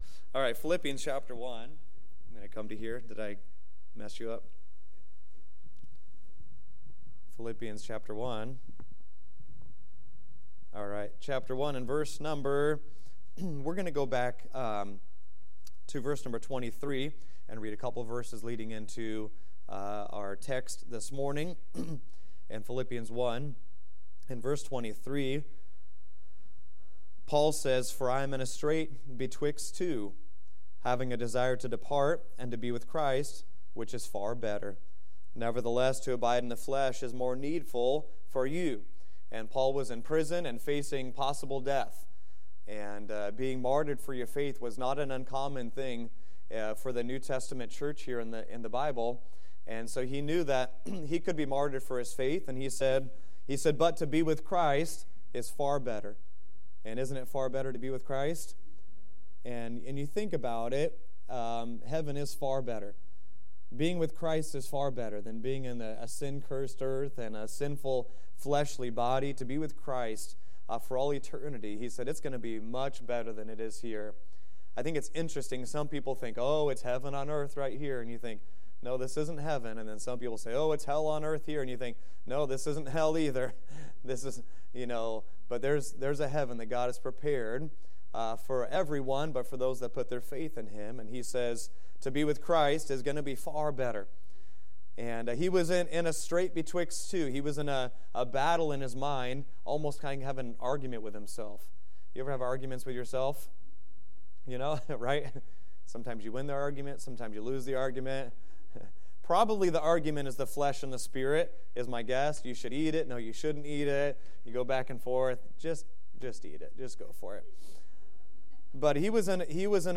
Challenge to the Believers | Sunday School – Shasta Baptist Church